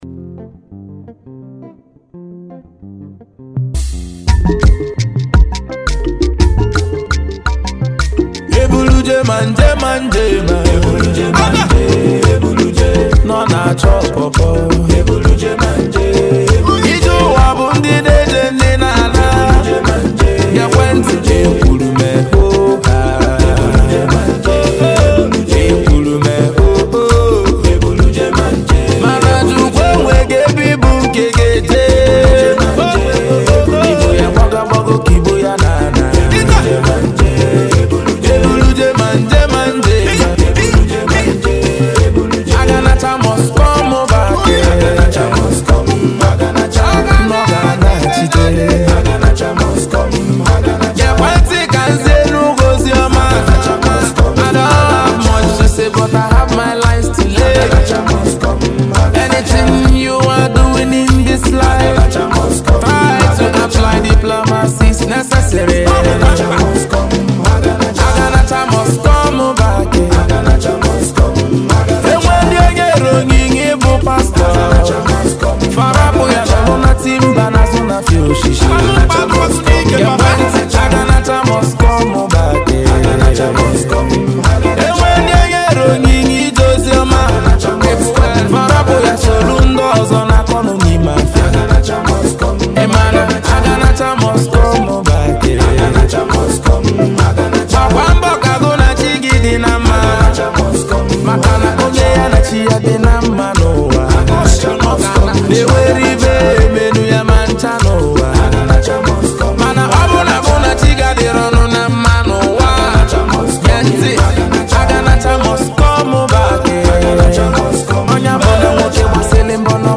October 15, 2024 admin Highlife Music, Music 0
deep sensational highlife single